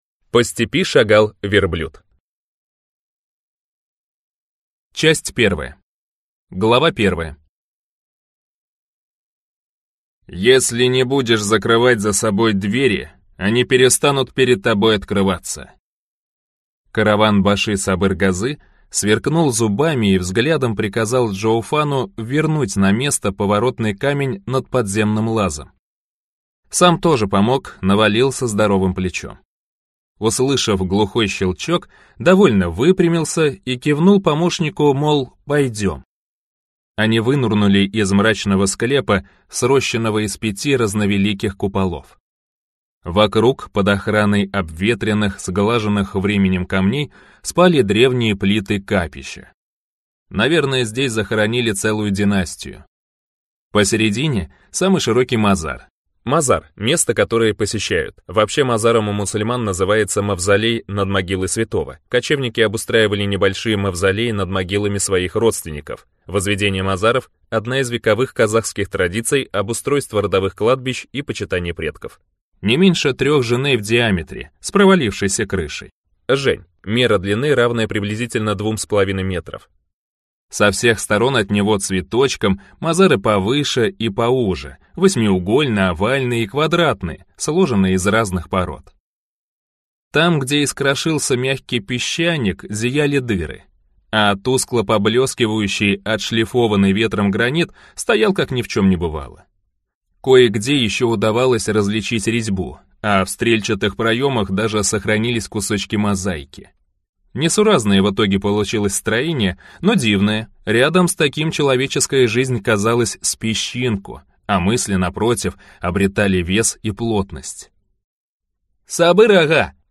Аудиокнига По степи шагал верблюд | Библиотека аудиокниг